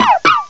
rockruff.aif